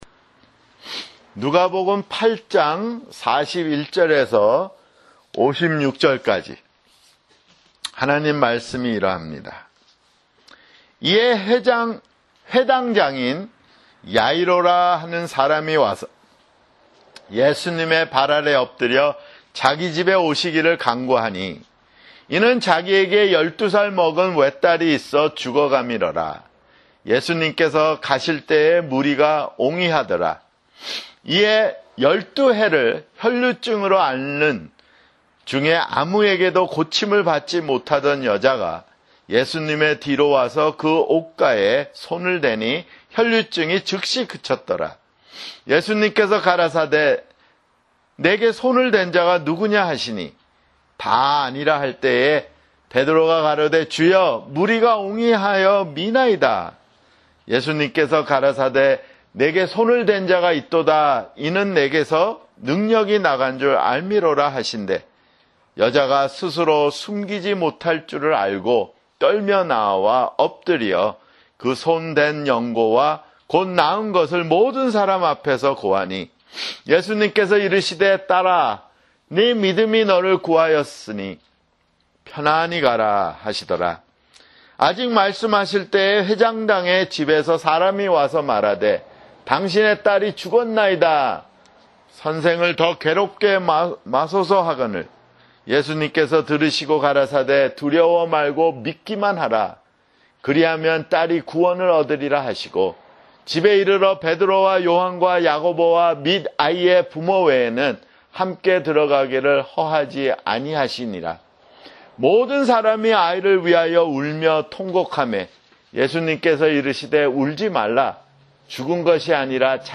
[주일설교] 누가복음 (60)